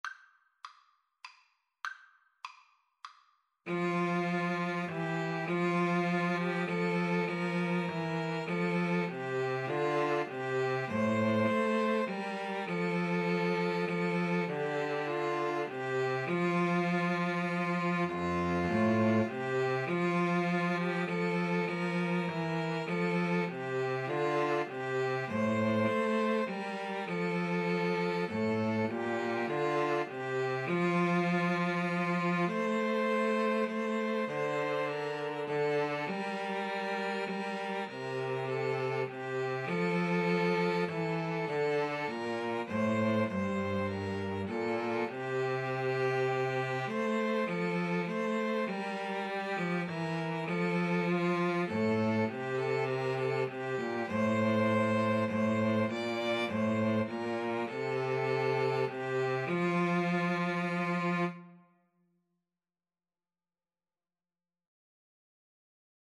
3/4 (View more 3/4 Music)
Classical (View more Classical 2-Violins-Cello Music)